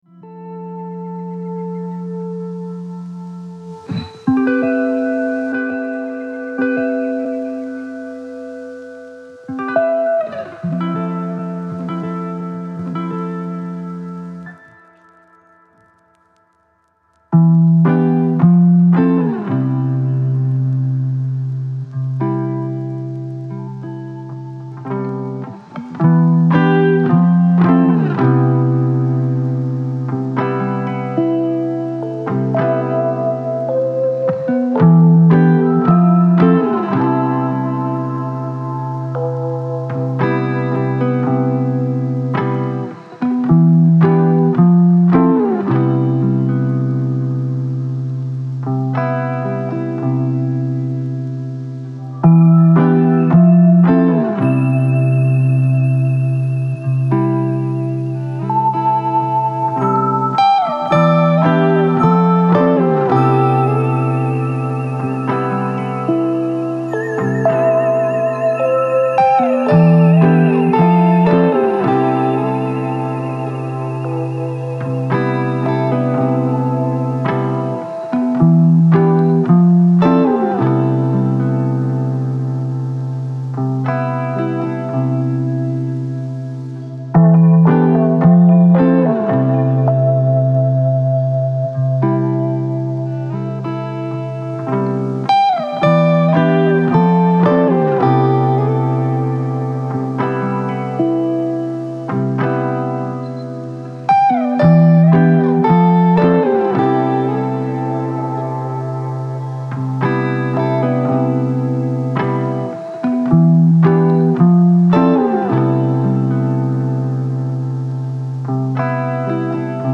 Intuition 852 Hz : Vision Intérieure